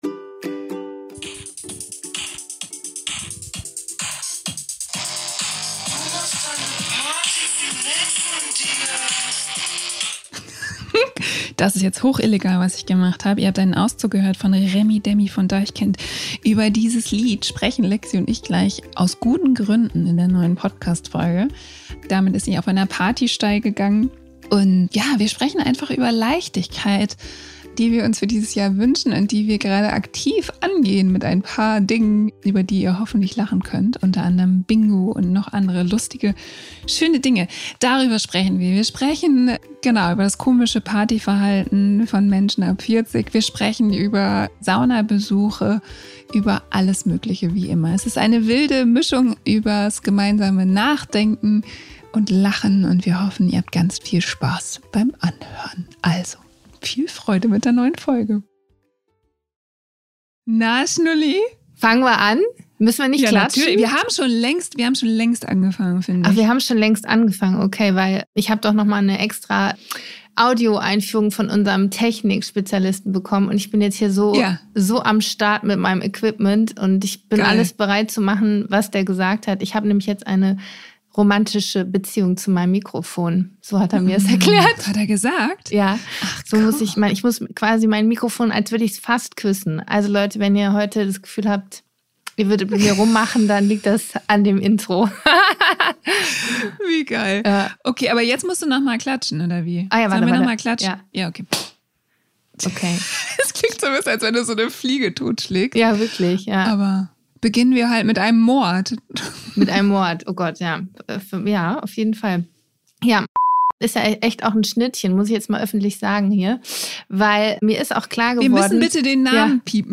Es wird wie immer viel gelacht und nachgedacht.